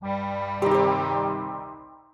SFX_Quest_Fail_Placeholder_02.wav